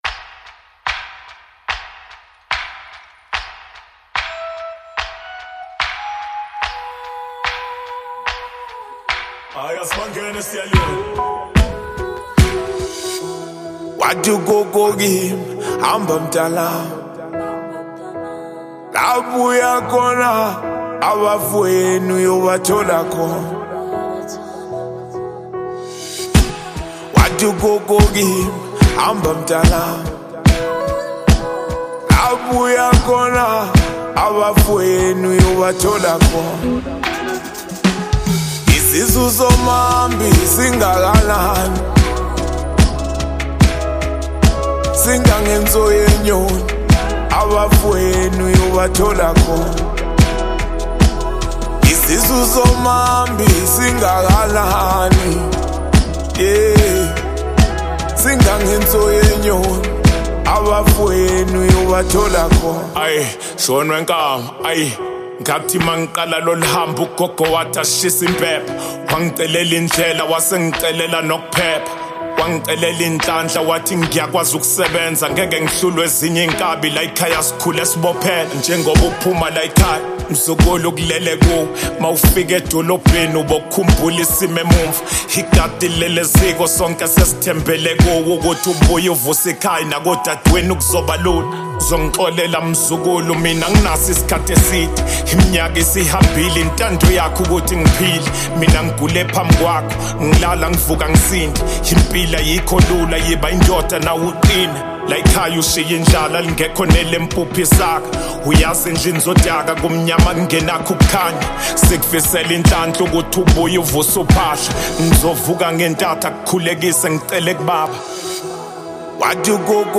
South African rapper